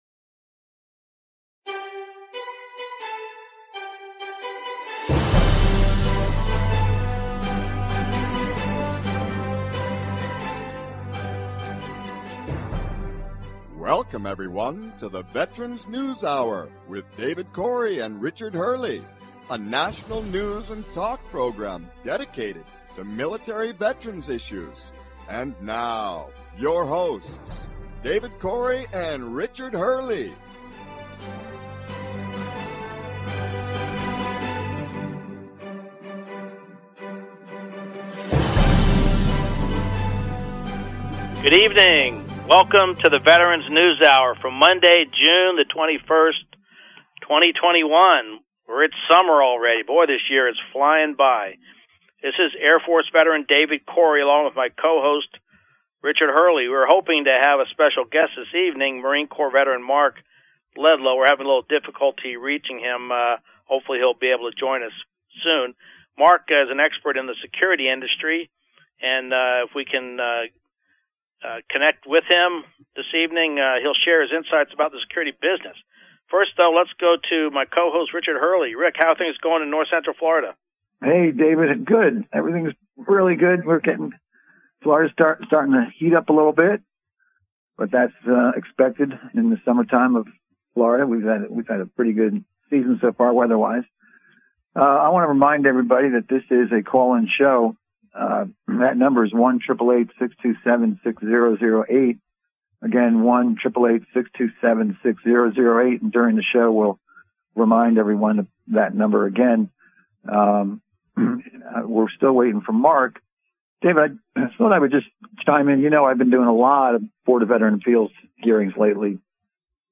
News and talk show about military veterans issues, including VA benefits and all related topics.